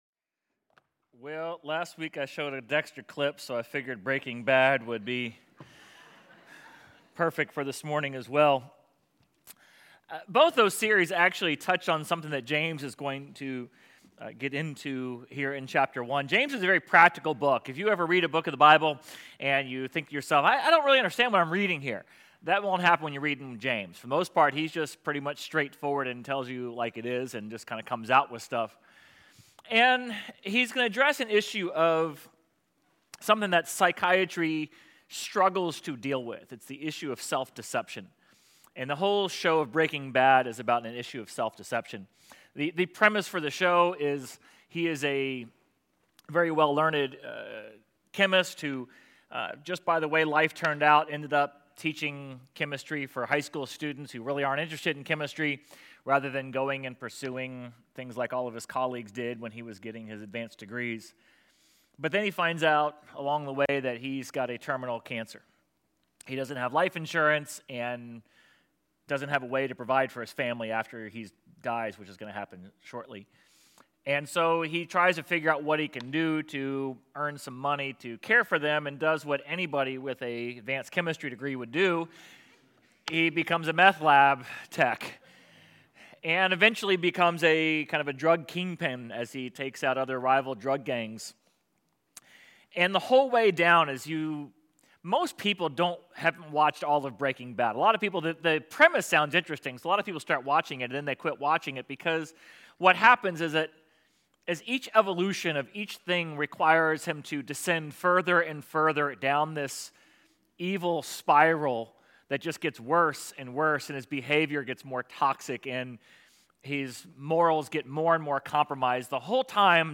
Sermon_6.8.25.mp3